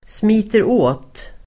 Ladda ner uttalet
smita åt verb, be a tight fit , fit tight Grammatikkommentar: x & Uttal: [smi:ter'å:t] Böjningar: smet åt, smitit åt, smita åt, smiter åt Definition: sitta tätt Exempel: kjolen smiter åt (the skirt is a tight fit)